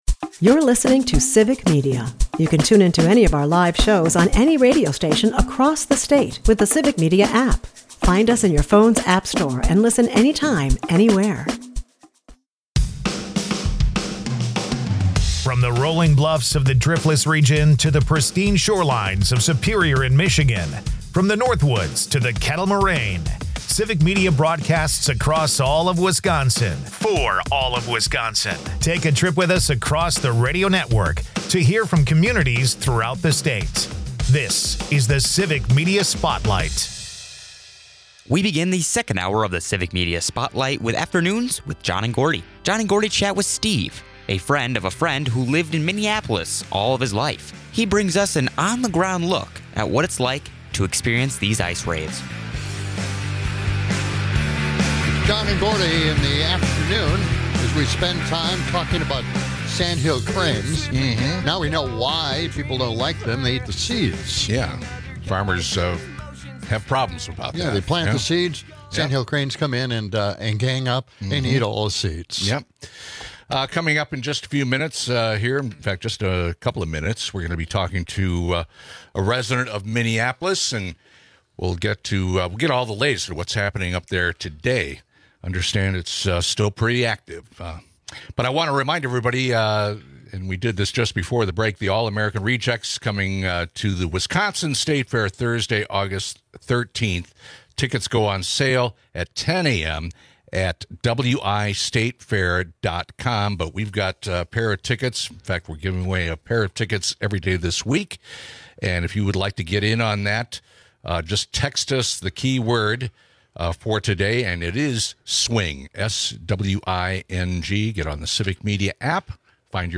Civic Media is a pro-democracy radio network serving local communities in Wisconsin. Hear a sample of our best programming from the past week from across our statewide network.